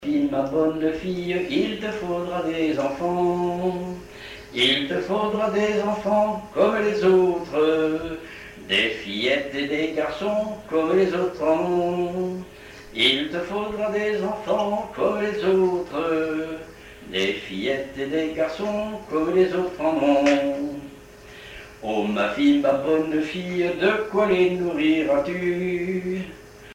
Dialogue mère-fille
gestuel : à virer au guindeau
Genre dialogue
Pièce musicale inédite